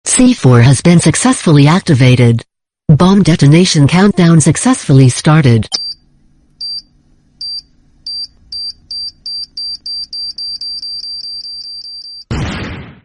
Bomb Sound Button - Free Download & Play
Sound Effects Soundboard583 views